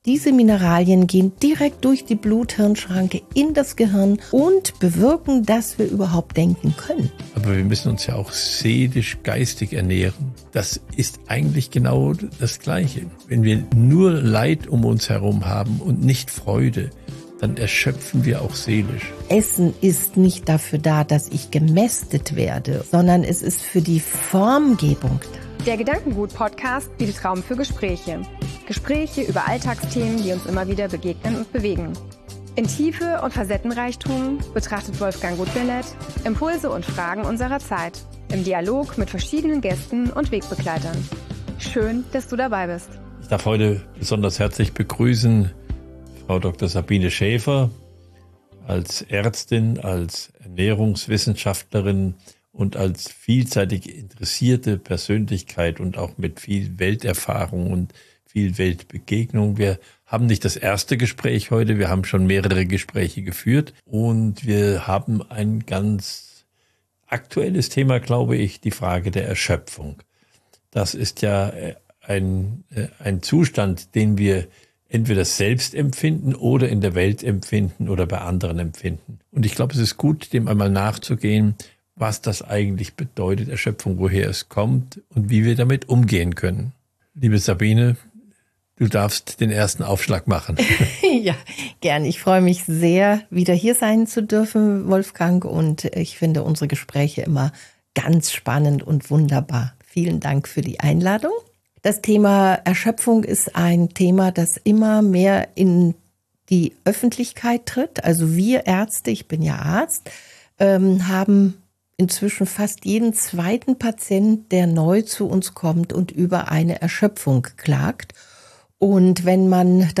Ein Gespräch voller Impulse für alle, die spüren: Es muss einen anderen Weg geben – zurück zu mehr Lebendigkeit, Klarheit und innerer Kraft.